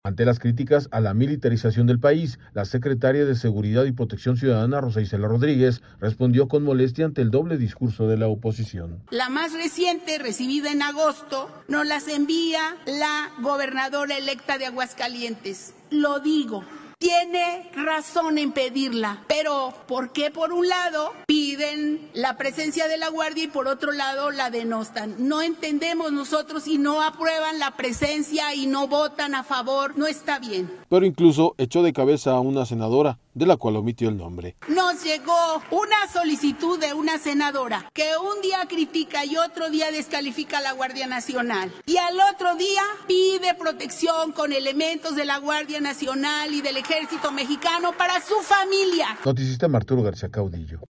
Ante las críticas a la militarización del país, la secretaria de Seguridad y Protección Ciudadana, Rosa Icela Rodríguez, respondió con molestia ante el doble discurso de la oposición.